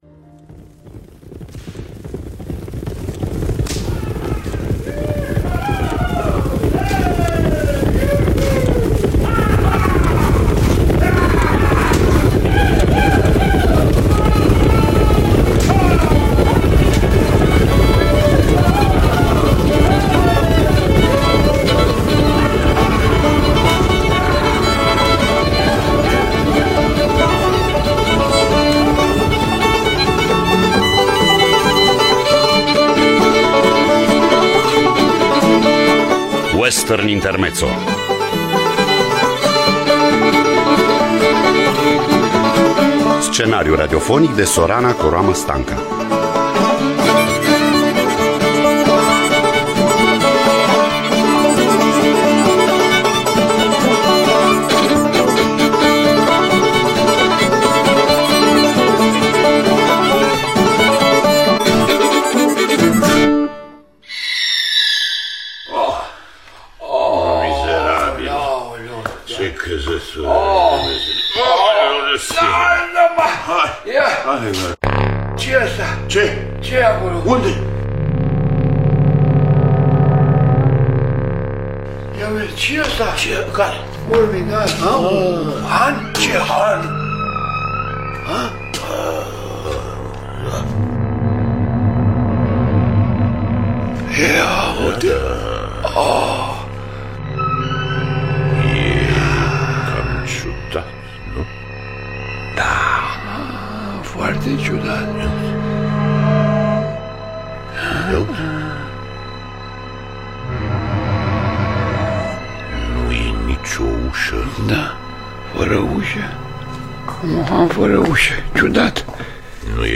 Western intermezzo de Sorana Coroamă-Stanca – Teatru Radiofonic Online
Percuție